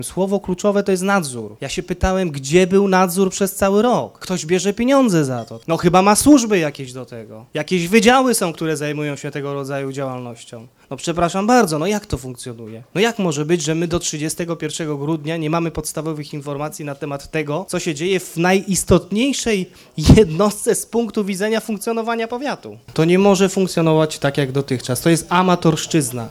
– To kropla w morzu potrzeb, a szpitalowi brakuje przede wszystkim dobrego nadzoru – mówił podczas sesji radny Zdzisław Rygiel.